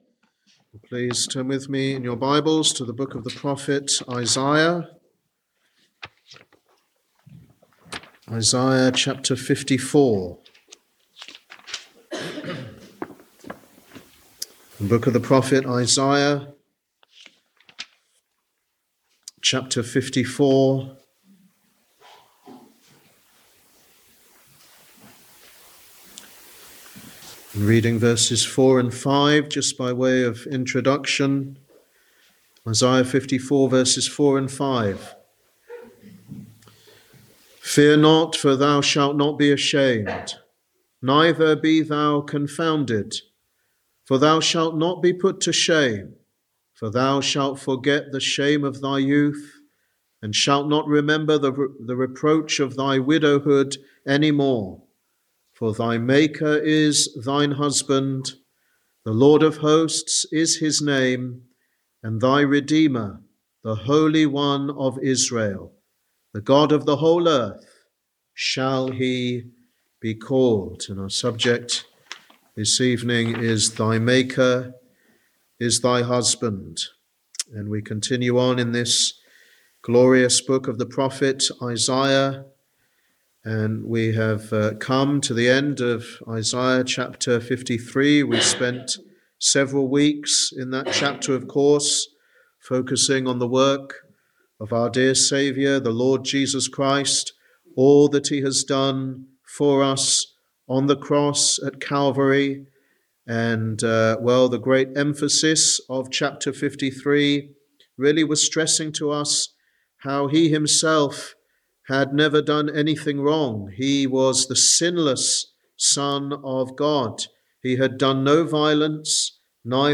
Wednesday Bible Study
Sermon